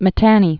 (mĭ-tănē, -tänē)